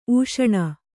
♪ ūṣaṇa